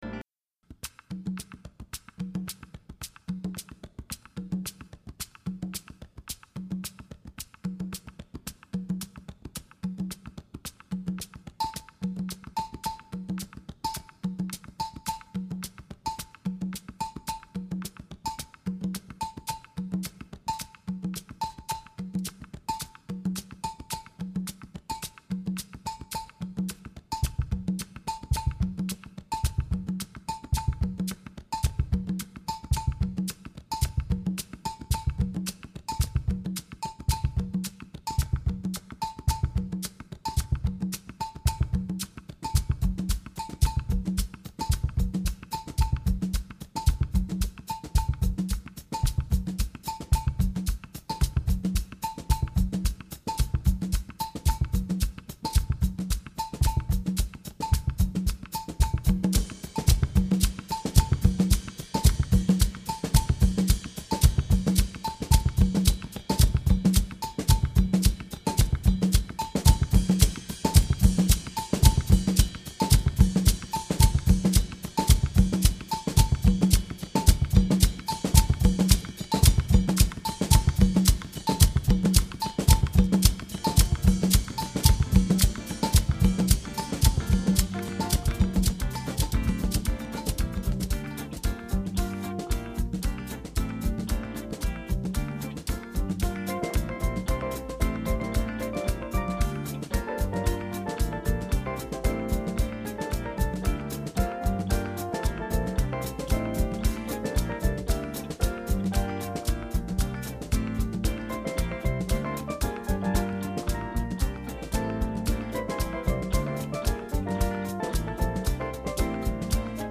Opening solos
Drivin' beat, soaring horns